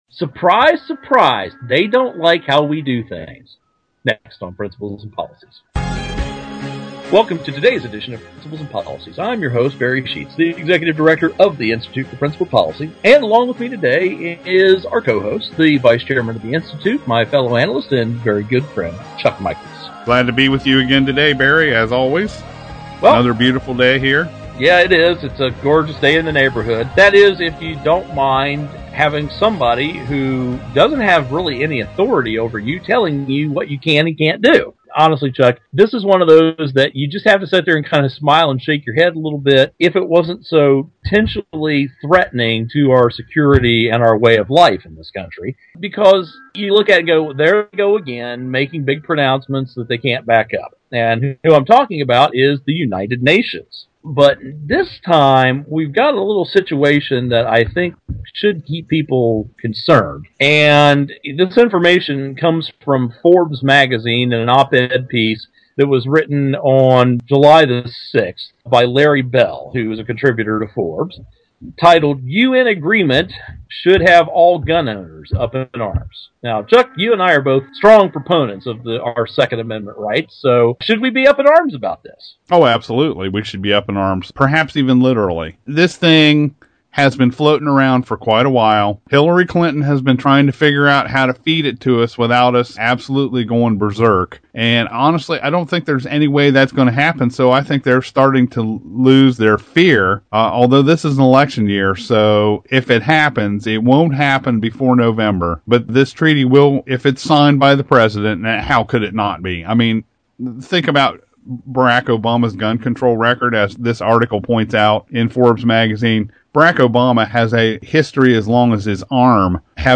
Our Principles and Policies radio show for Tuesday July 10, 2012.